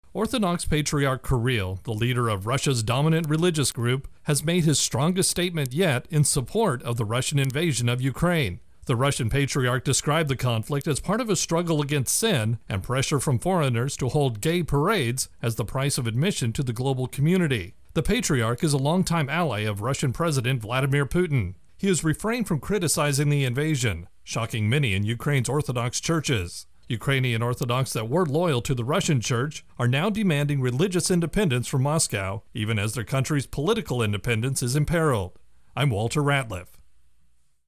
Intro and voicer for Russia Ukraine War Orthodox Schism